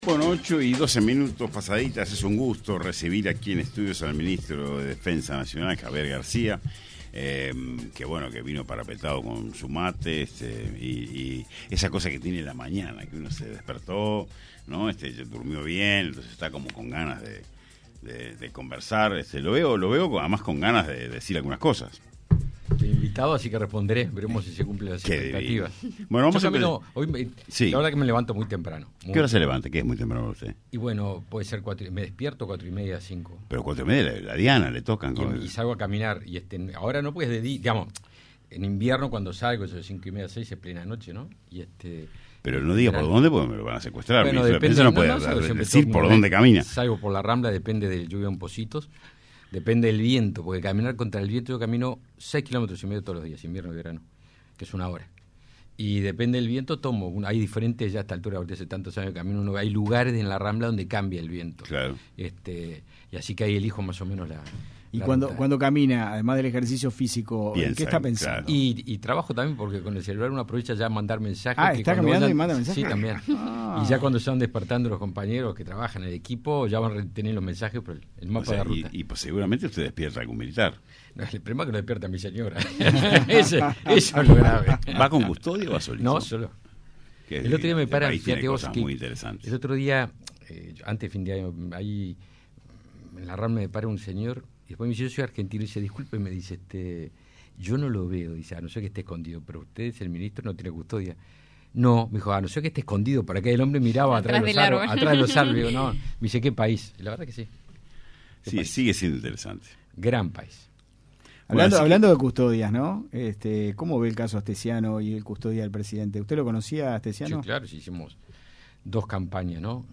El ministro de Defensa, Javier García, se refirió en entrevista con 970 Noticias a las candidaturas y manifestó que el dilema en las elecciones del 2024 será elegir entre un modelo “humanista y plural” o uno de corte “político sindical” representado por el Frente Amplio.